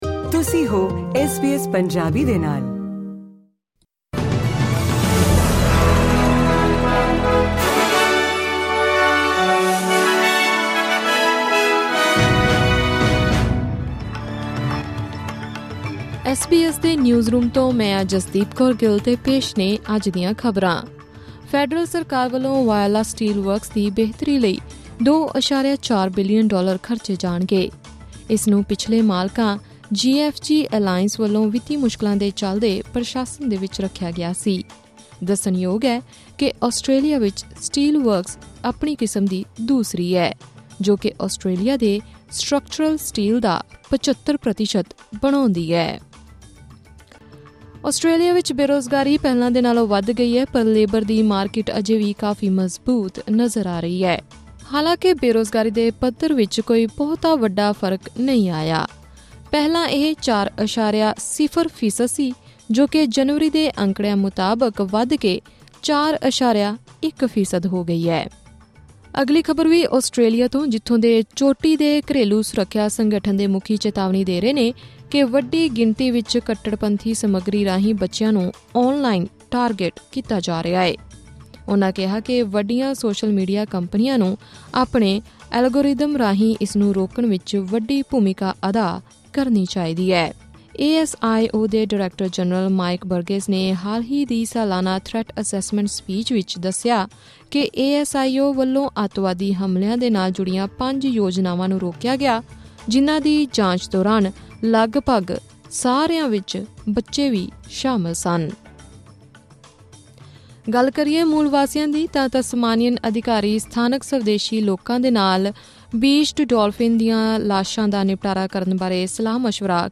ਖ਼ਬਰਨਾਮਾ: ਆਸਟ੍ਰੇਲੀਆ ਦੀ ਬੇਰੁਜ਼ਗਾਰੀ ਦਰ 'ਚ ਵਾਧਾ